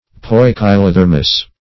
Poikilothermous \Poi`ki*lo*ther"mous\ (-m[u^]s), a. (Physiol.)